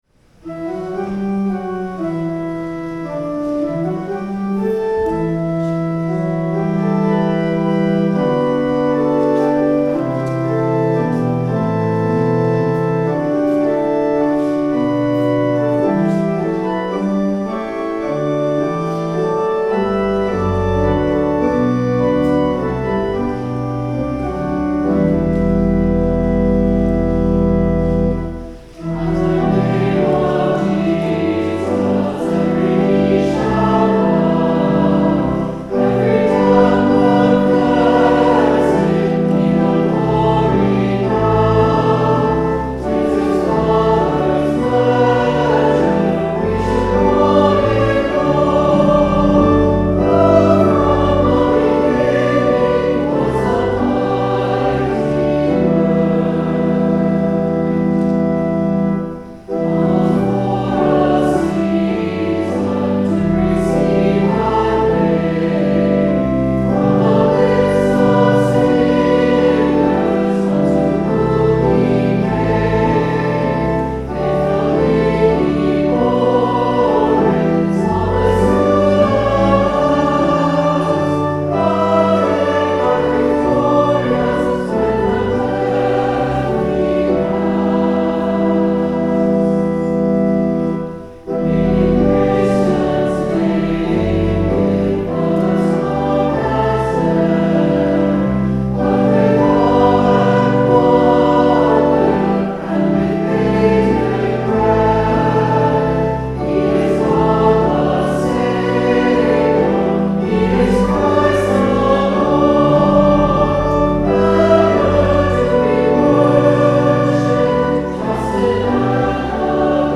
*We had a recording glitch this Sunday. The Collect and Gospel reading included here were re-recorded following the service.